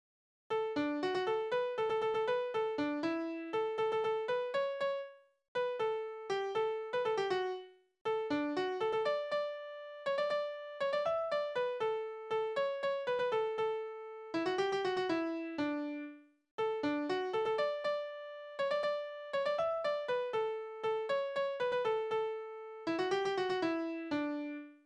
Tonart: D-Dur
Taktart: 3/8
Tonumfang: Oktave
Besetzung: vokal
Anmerkung: verschiedene Taktarten